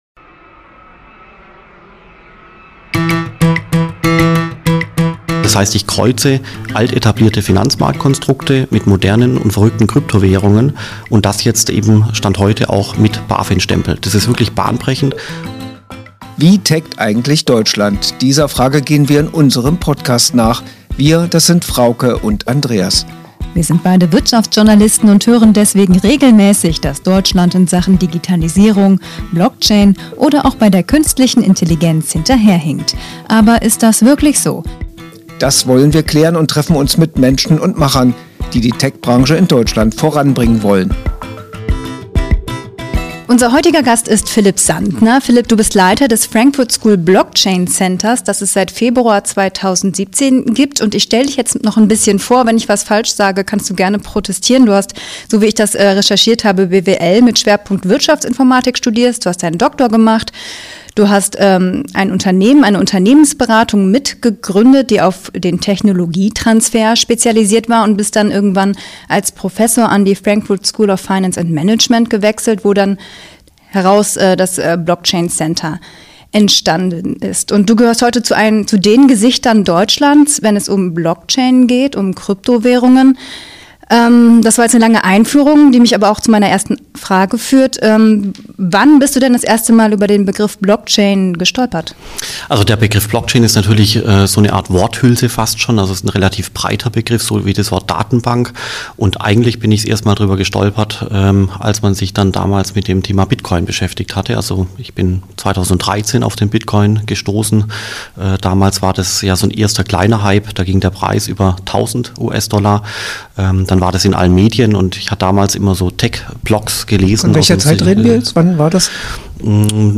zurückhaltend, fast schon schüchtern